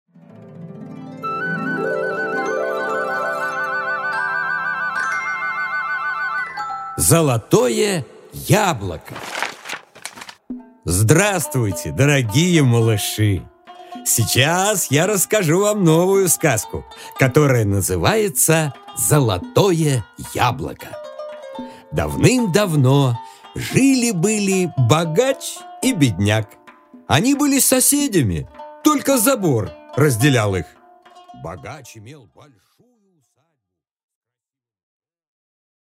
Аудиокнига Золотое яблоко | Библиотека аудиокниг